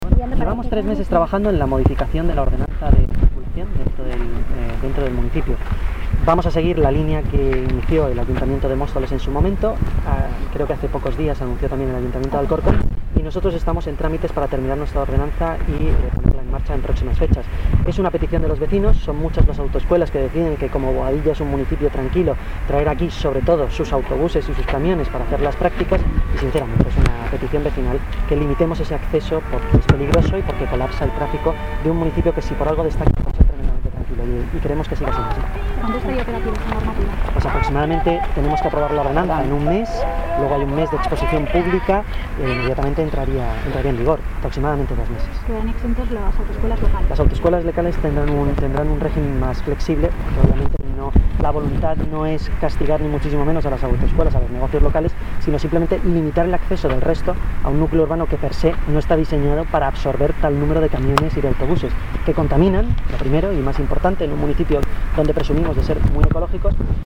Escuche las declaraciones de Antonio González Terol